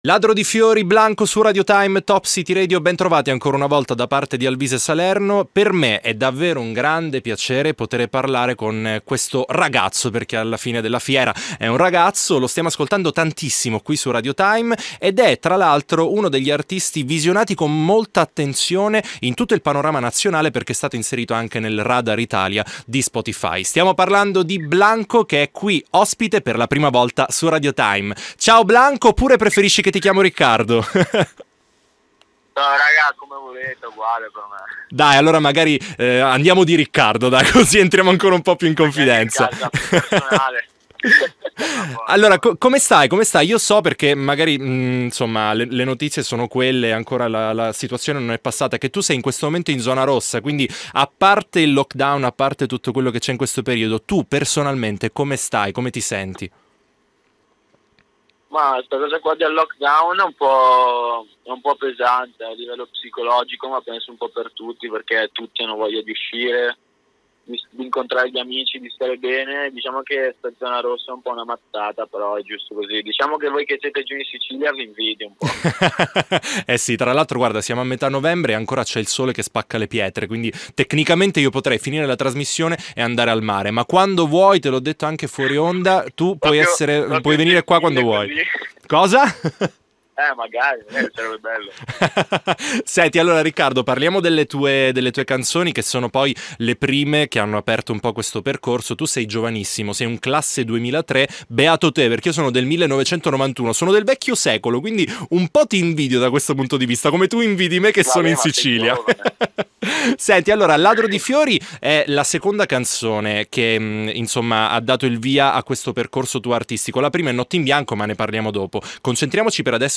T.I. Intervista Blanco